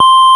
FARFISA4D C5.wav